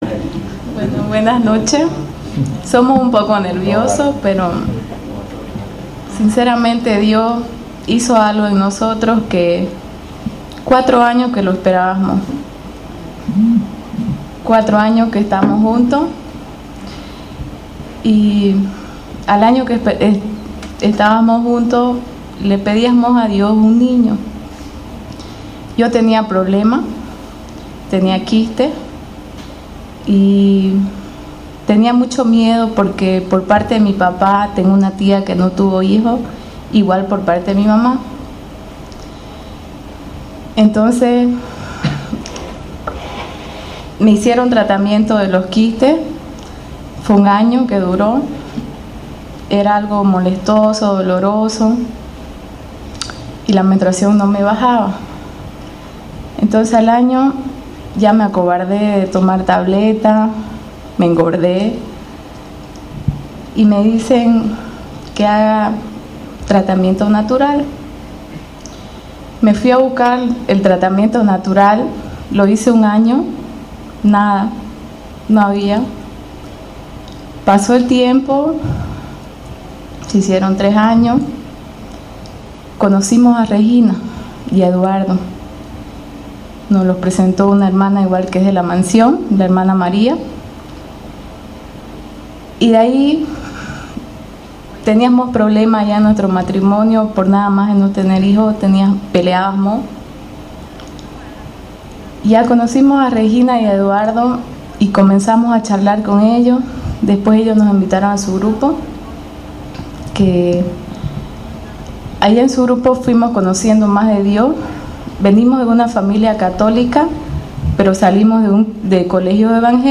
Testimonio de Embarazo